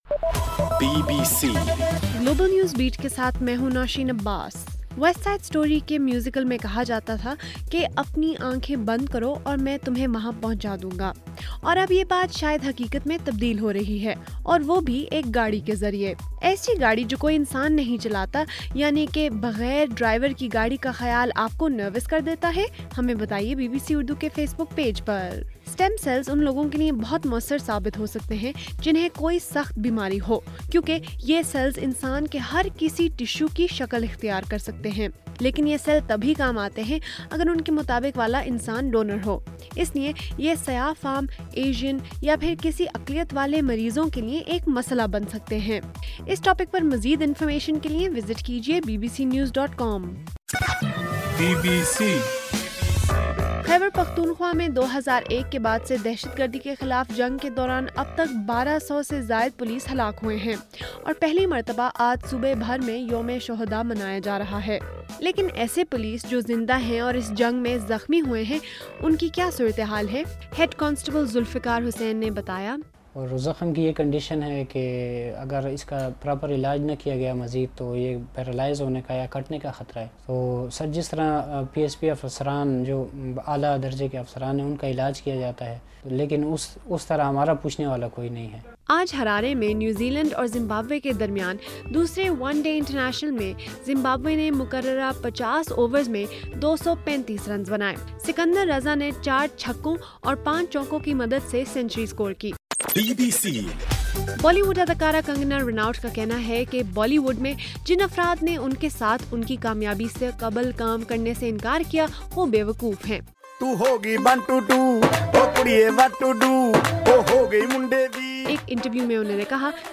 اگست 4: رات 8 بجے کا گلوبل نیوز بیٹ بُلیٹن